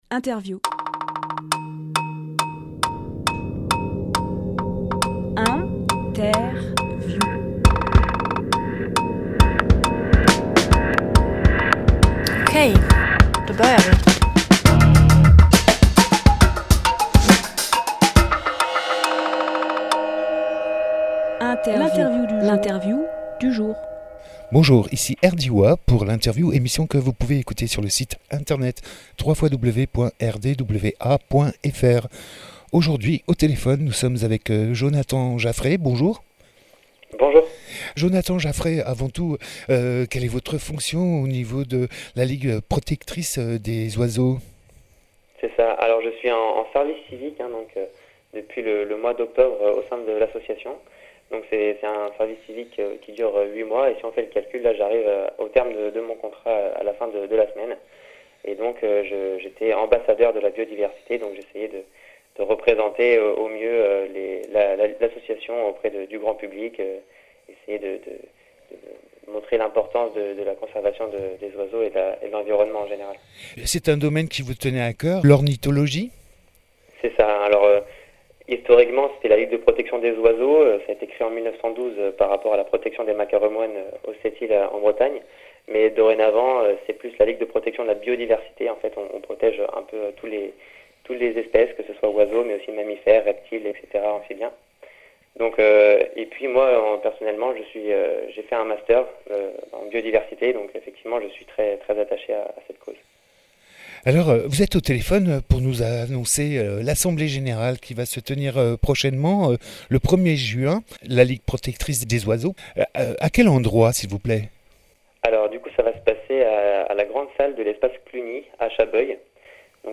Emission - Interview Assemblée Générale de la LPO à Chabeuil Publié le 28 mai 2018 Partager sur…
Lieu : Studio RDWA